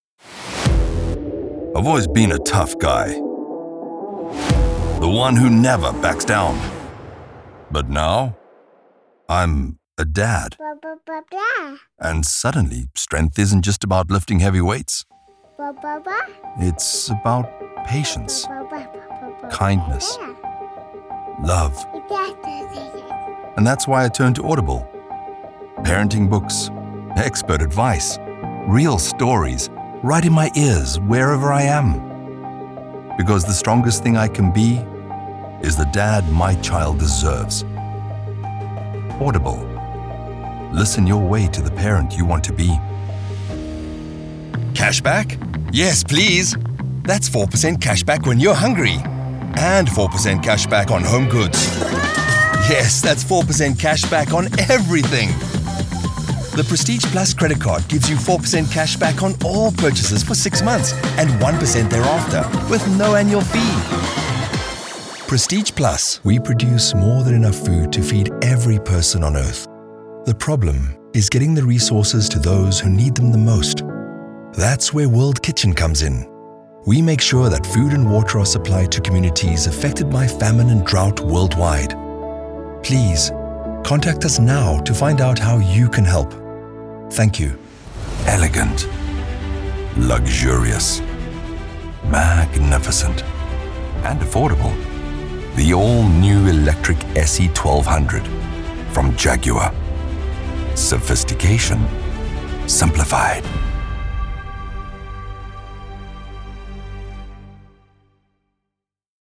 Inglês (sul-africano)
Confiável
Autoritário
Esquentar